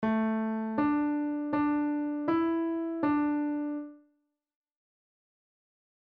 Piano Notes